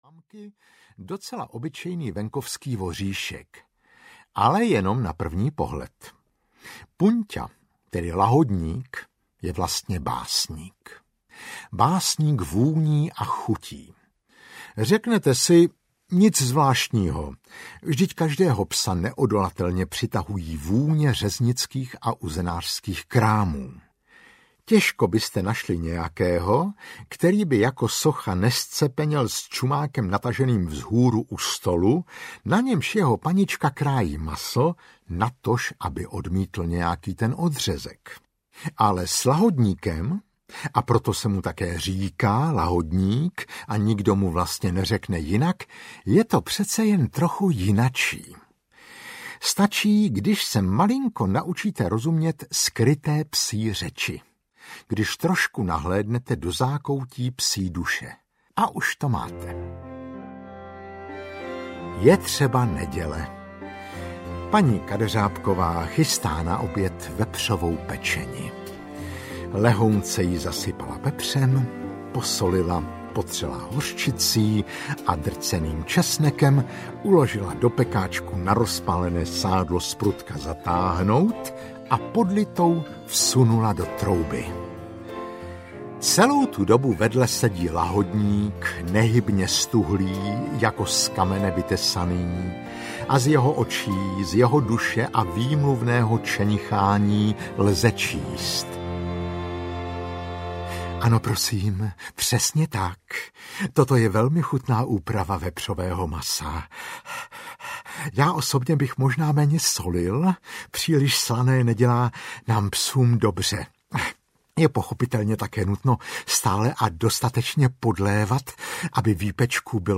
Slůňadla audiokniha
Ukázka z knihy
• InterpretMarek Eben